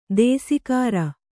♪ dēsikāra